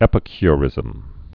(ĕpĭ-ky-rĭzəm, ĕpĭ-kyrĭz-əm)